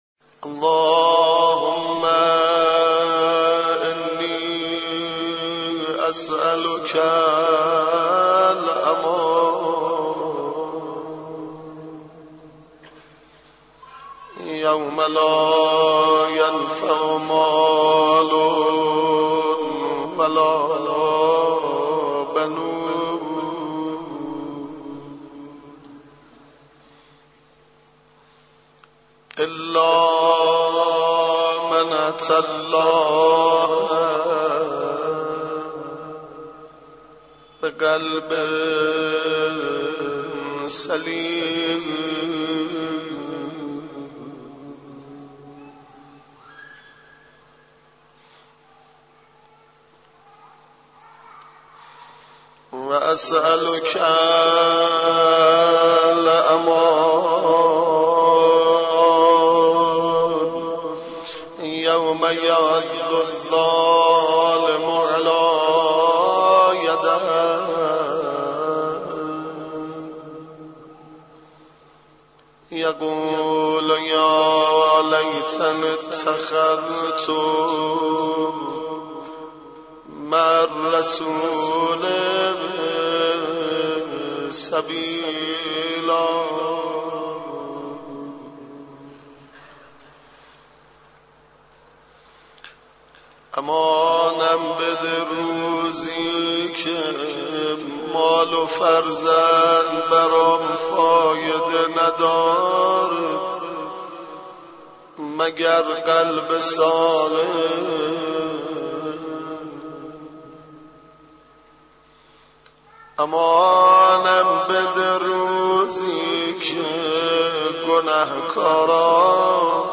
monajat-emam-ali-high.mp3